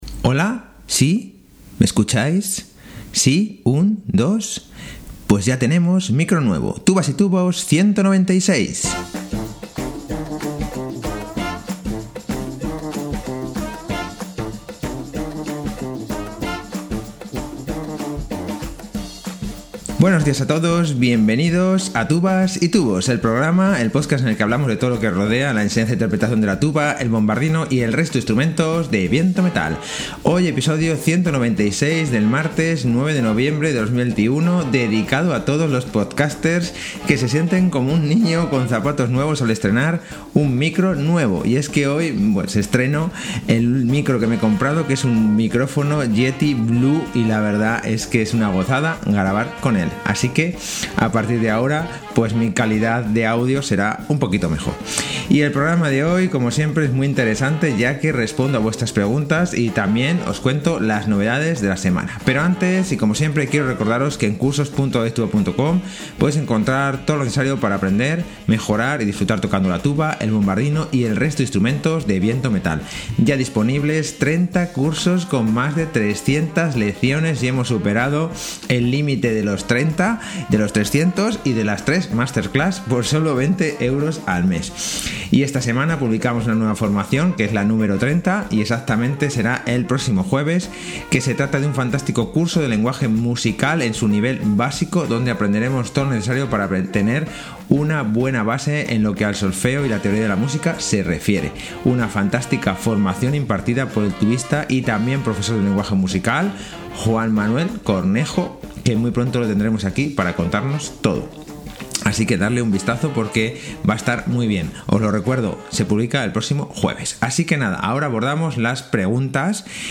Hoy, episodio 196 del martes 9 de noviembre de 2021 dedicado a todos los podcasters que se sienten como un niño con zapatos nuevos al estrenar un micro nuevo, y es que me he comprado un micrófono Yeti blue y la verdad es que es una gozada grabar con él.